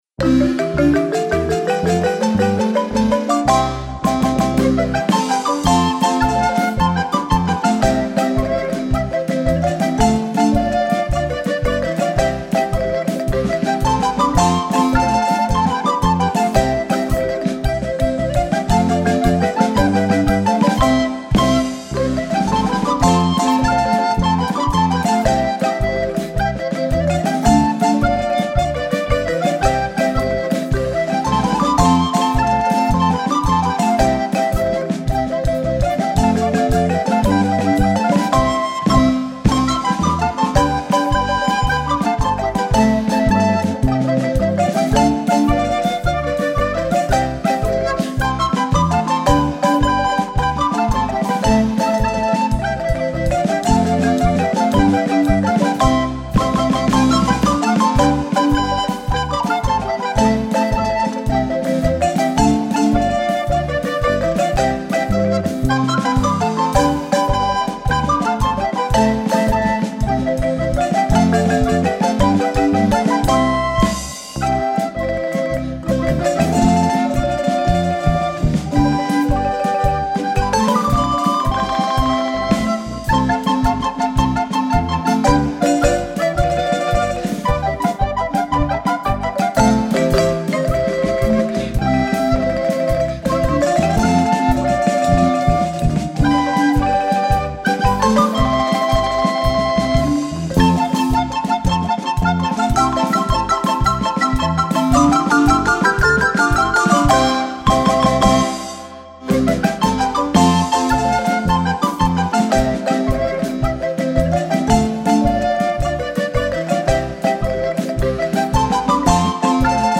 flautas